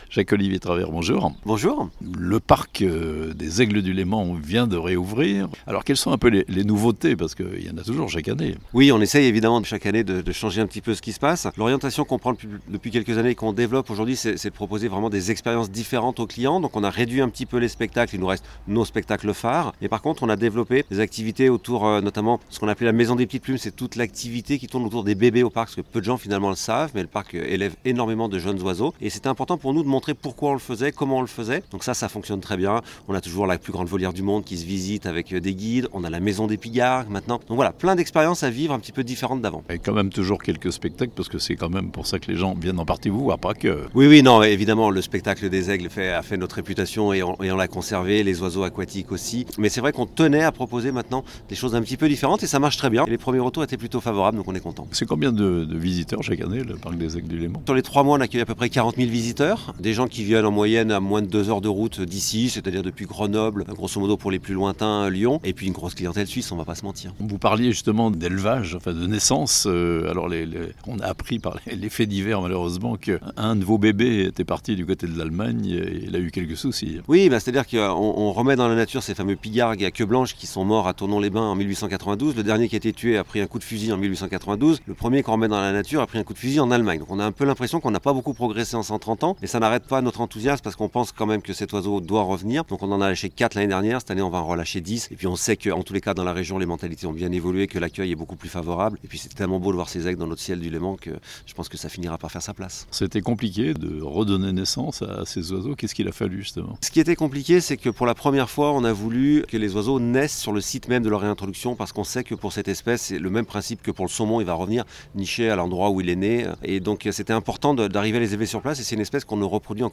Le parc des Aigles du Léman à Sciez a réouvert ses portes depuis quelques jours (interview)